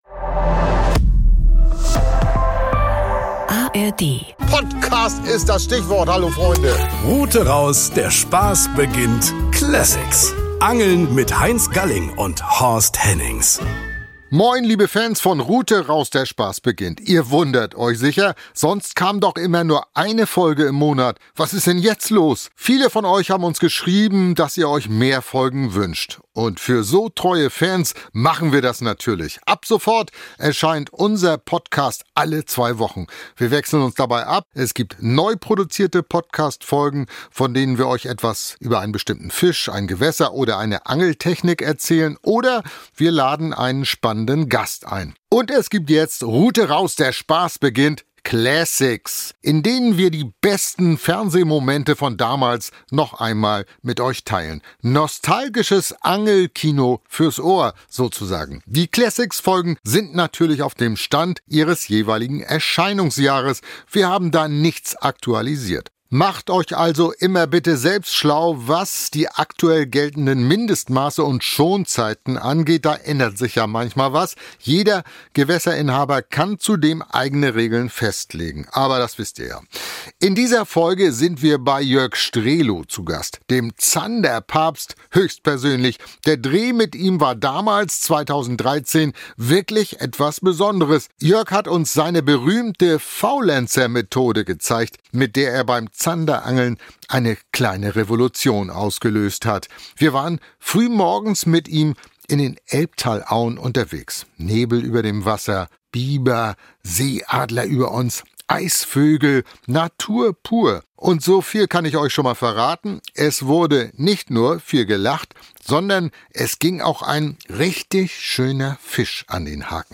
Direkt vor Ort, am Lagerfeuer, wird das Fischgericht zubereitet: gedünsteter Zander in Alufolie.